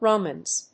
発音記号
• / ˈromʌnz(米国英語)
• / ˈrəʊmʌnz(英国英語)